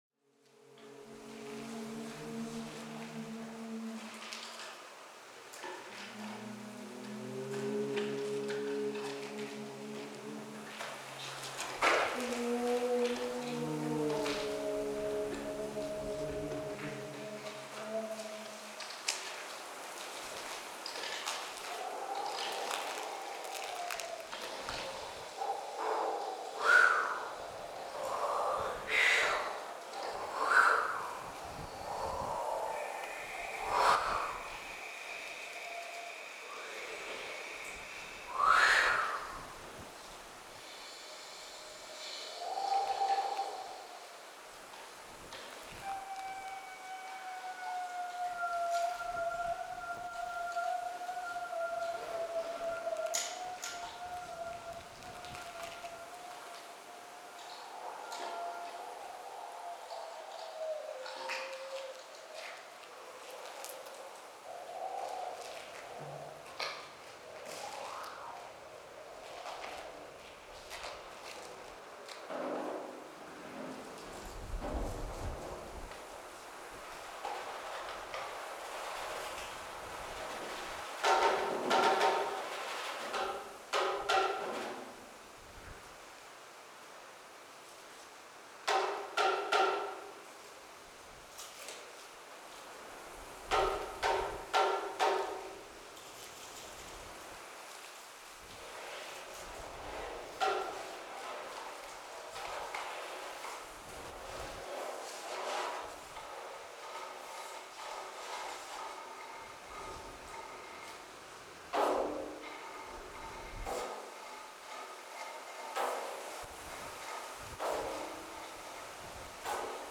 '2 voices, drum, wind, fire & reed'